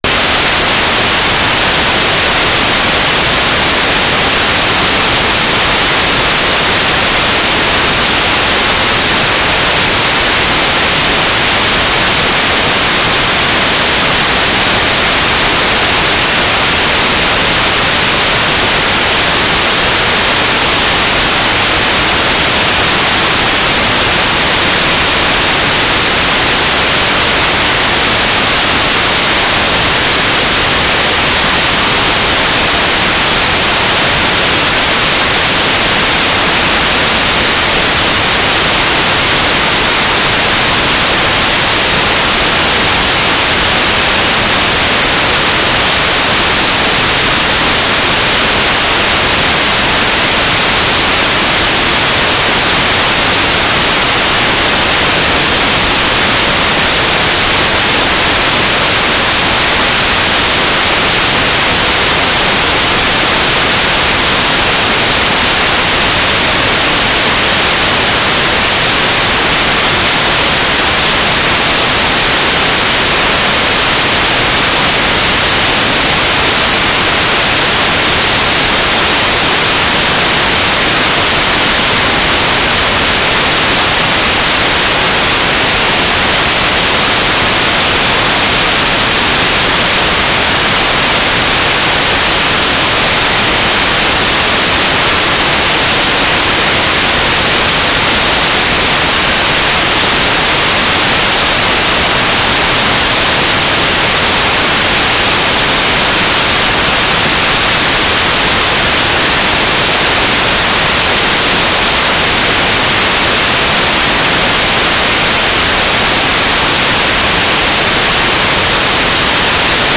"transmitter_description": "Mode U - GMSK2k4 - USP",